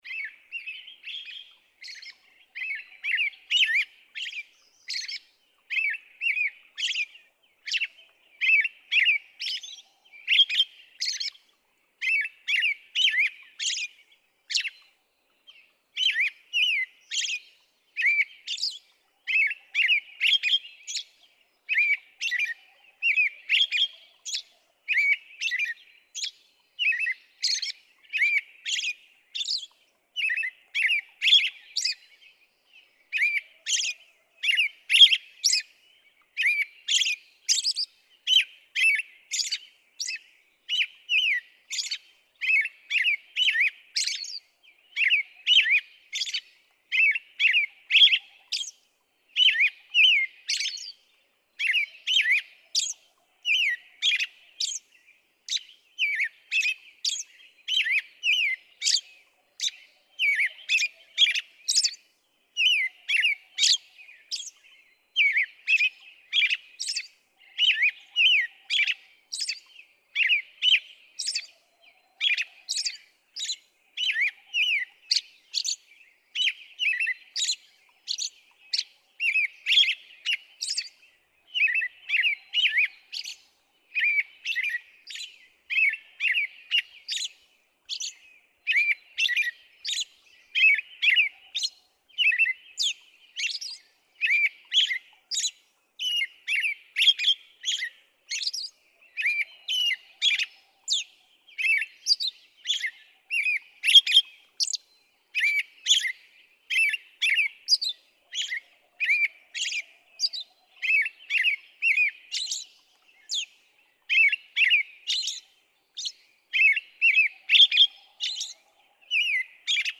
♫15. Song: carols and hissellys.
Grand Teton National Park, Wyoming.
015_American_Robin.mp3